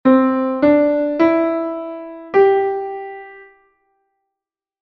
Nota de paso
DO-RE-MI-SOL